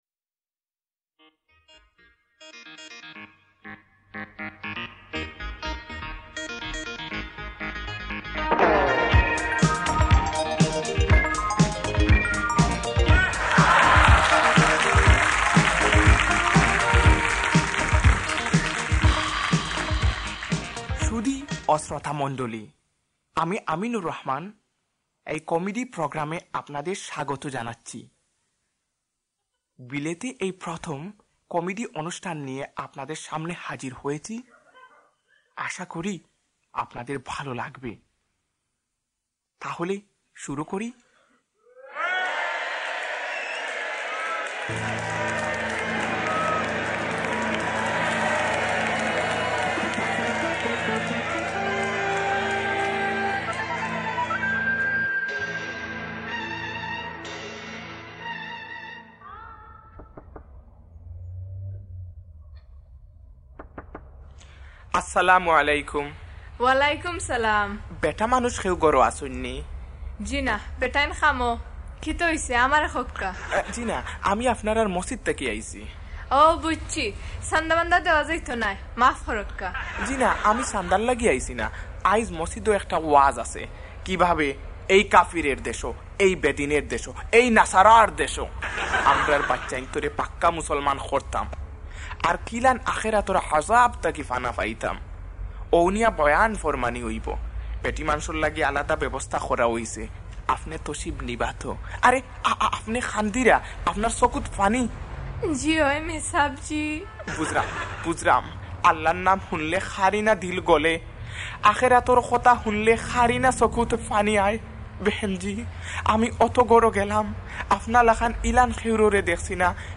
Dunia Part 1 – Comedy